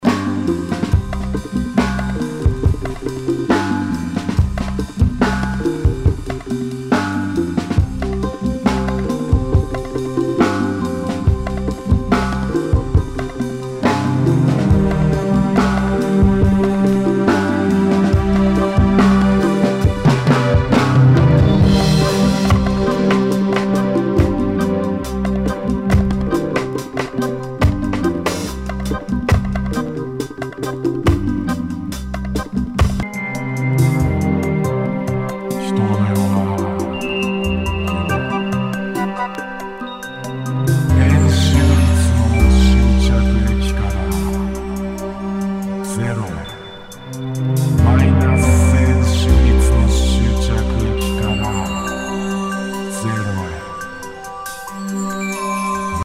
Nu- Jazz/BREAK BEATS
ナイス！ダウンテンポ！